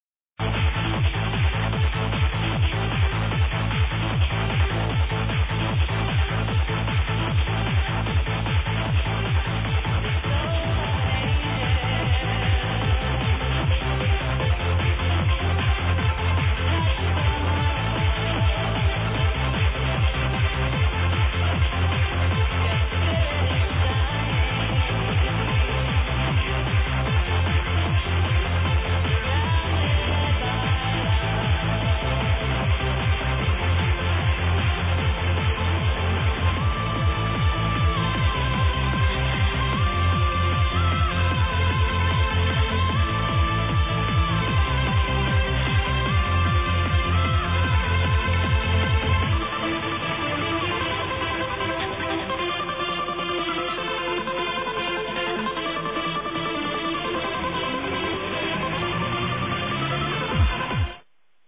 The only pulserdriver tune I know that is not fully cheesy.